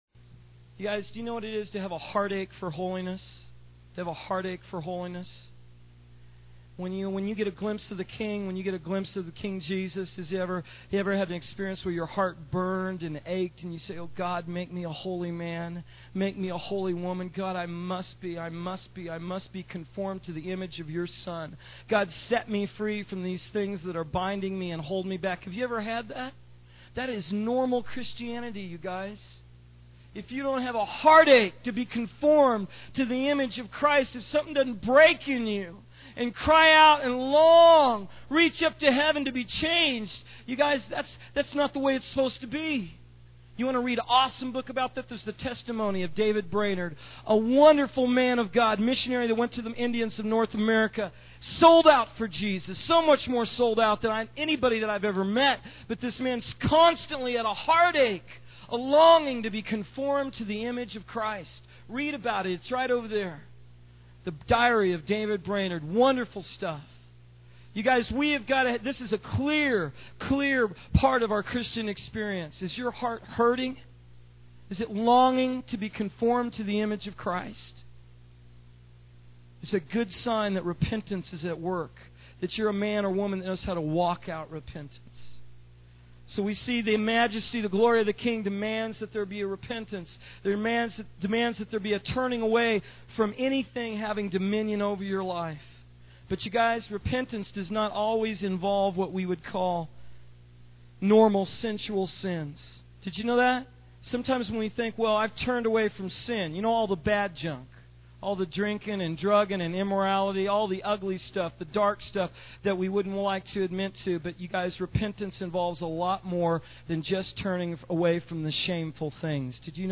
In this sermon, the preacher focuses on Mark 1:14-22, where Jesus begins his ministry by preaching the gospel of the kingdom of God.